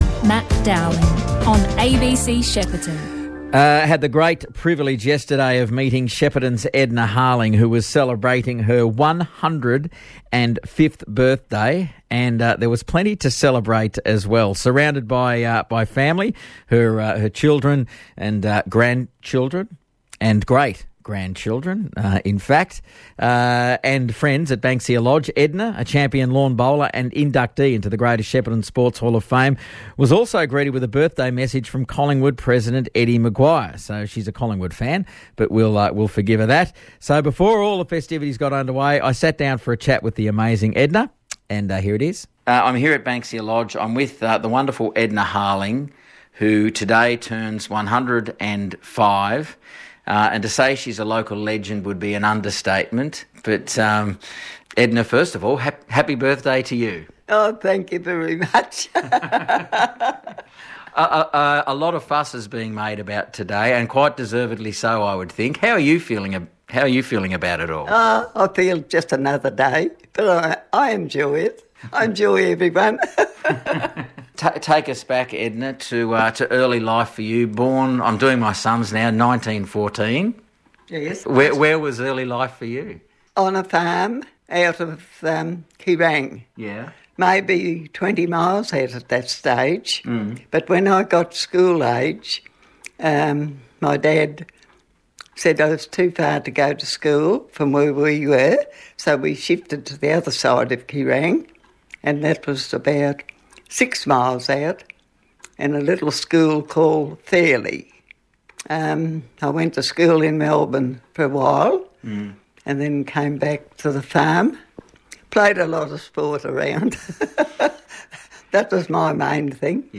Audio credit: ABC Radio Shepparton.